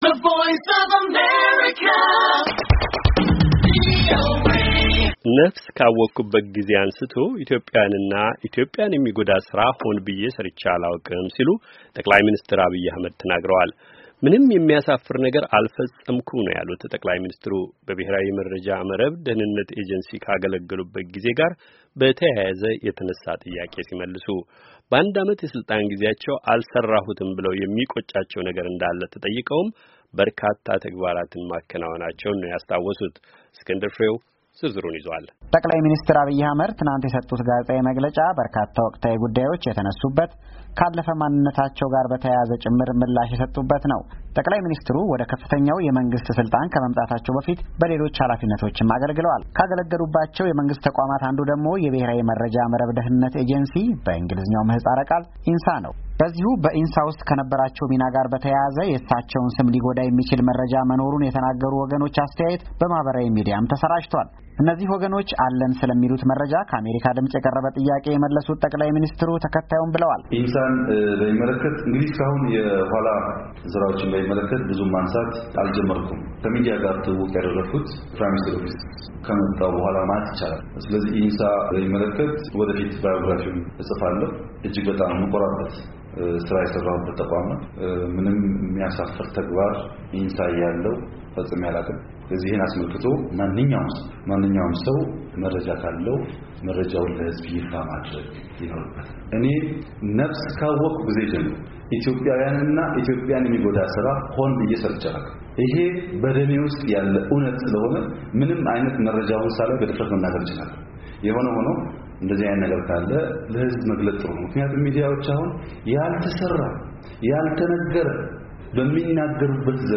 ጠ/ሚ ዐብይ ለጋዜጠኞች የሰጡት መግለጫ